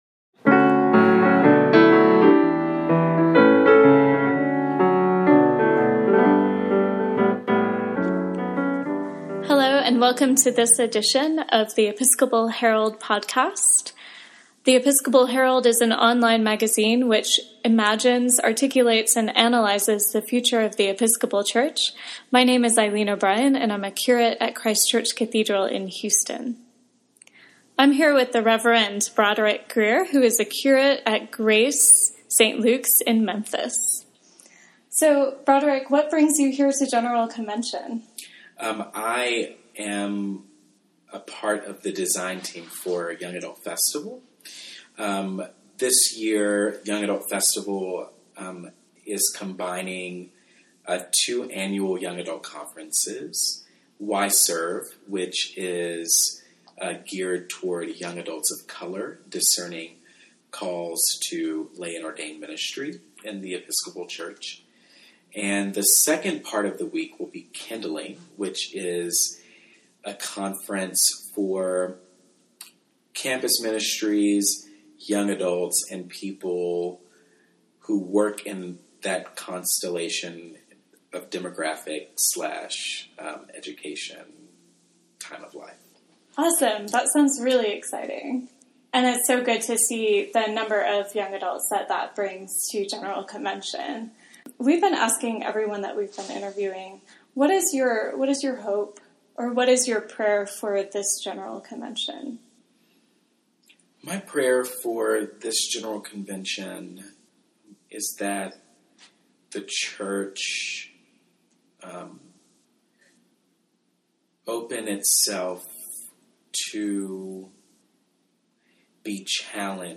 Episcopal Herald Podcast – Conversation Series